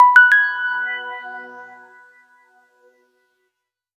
InfoComputerNotification.wav